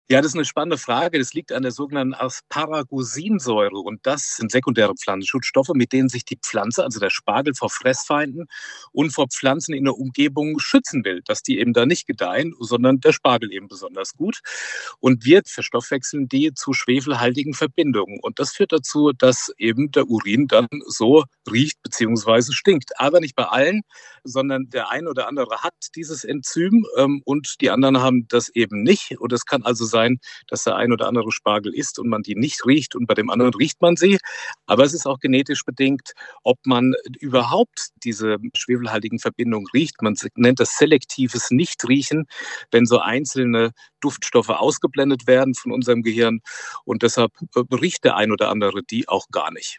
Ernährungswissenschaftler